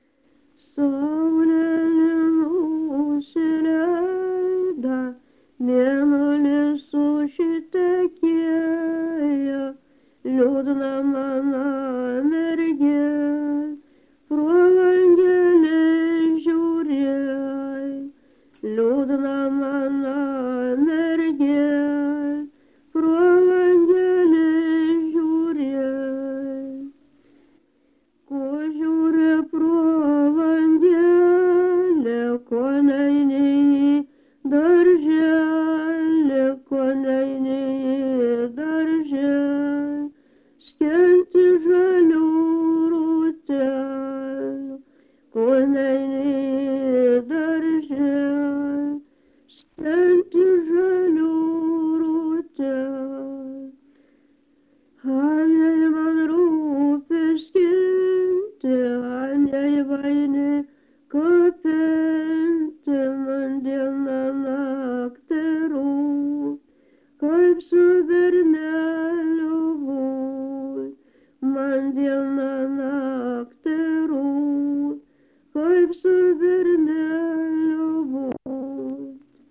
Struikai
vokalinis
Komentaras? (nesuprantamas dėl garso kokybės)